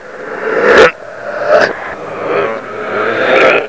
deadsignal2.wav